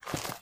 STEPS Dirt, Walk 30.wav